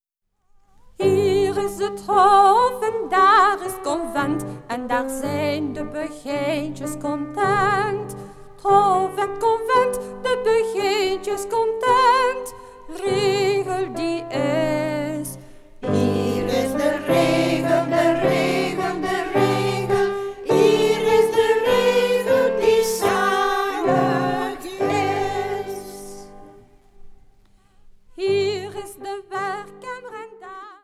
begijnen van het Groot Begijnhof van Sint-Amandsberg
Klavier en solozang:
Studio G.S.T. Gent.